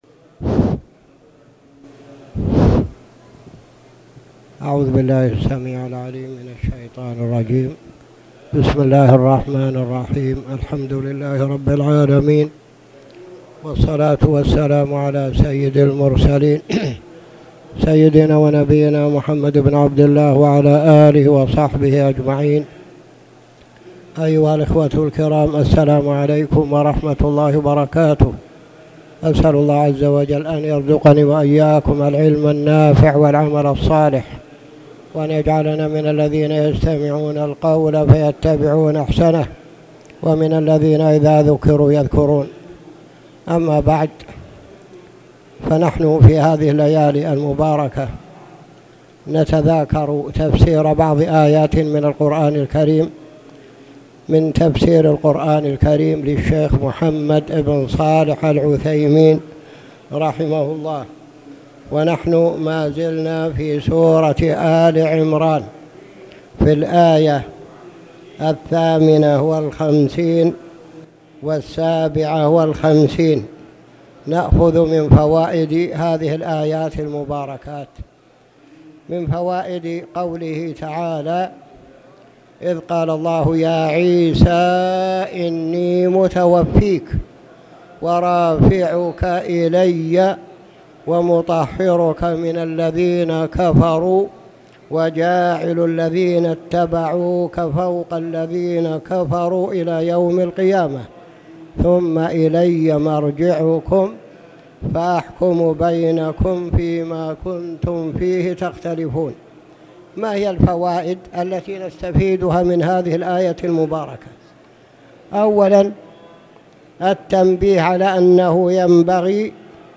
تاريخ النشر ٥ جمادى الآخرة ١٤٣٨ هـ المكان: المسجد الحرام الشيخ